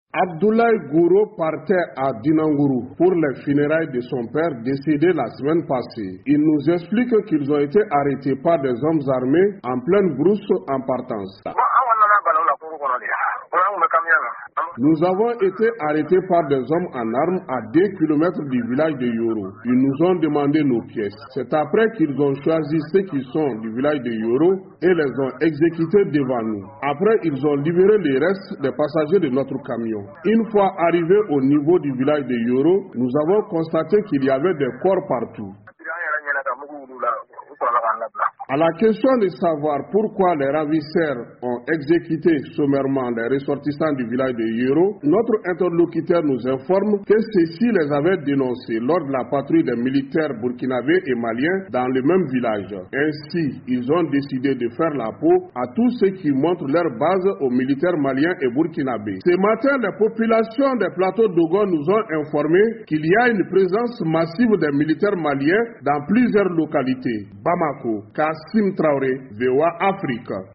Témoignage d'un rescapé de la dernière tuerie au Mali